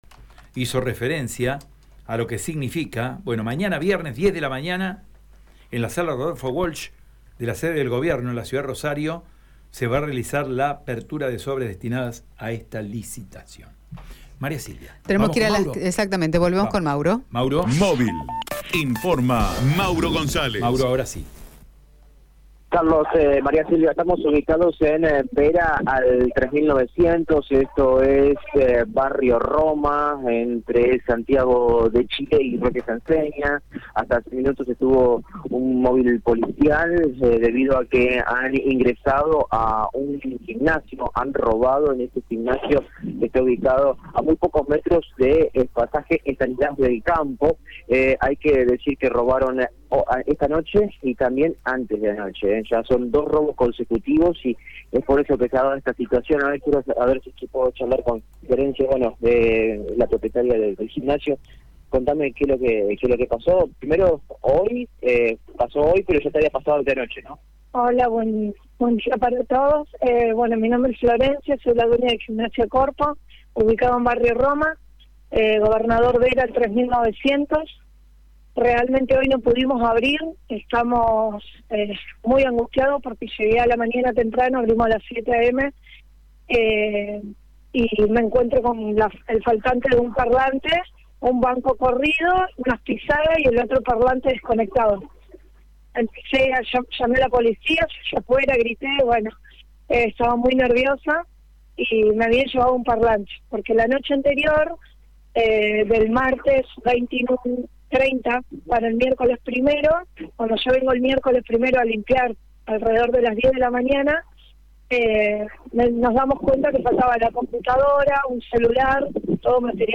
En diálogo con el móvil de Radio EME